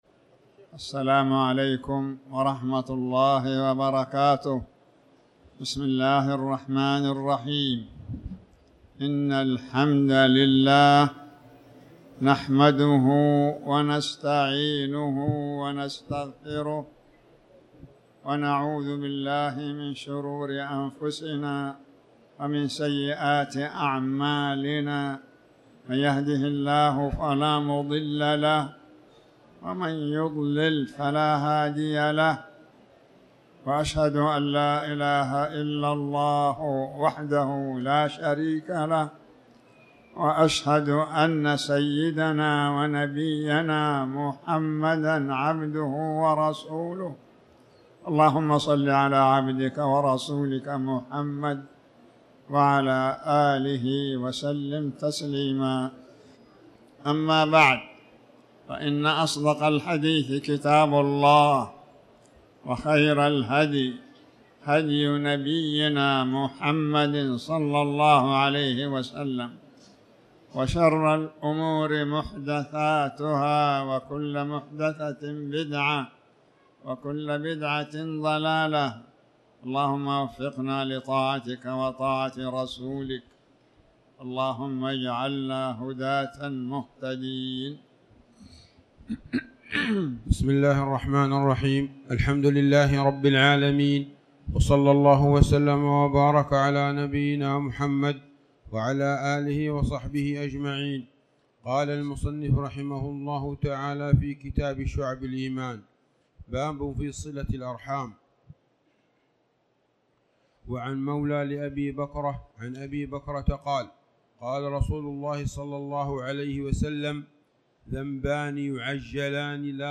تاريخ النشر ٢٨ جمادى الأولى ١٤٤٠ هـ المكان: المسجد الحرام الشيخ